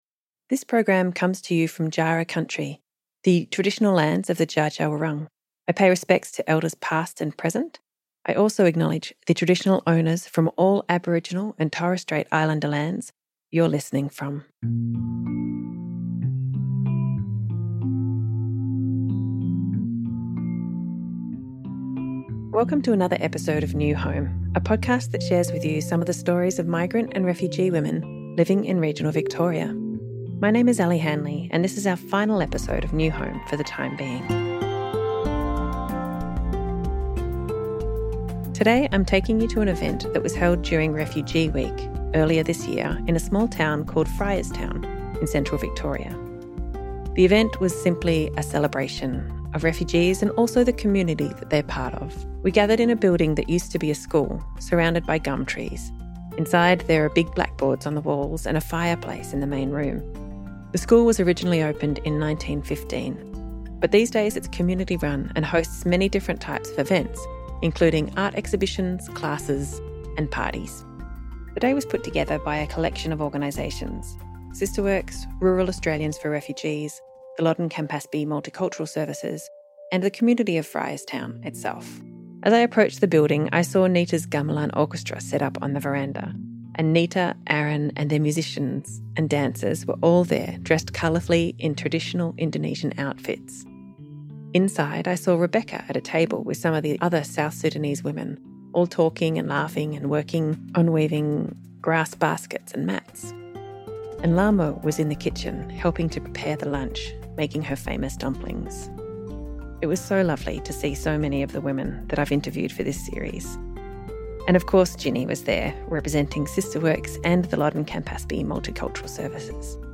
But each one has her own story and reasons for travelling across the world to put down new roots in a strange land. On New Home, hear from migrant and refugee women who are quietly building new lives in regional Australia, making friends, and finding community.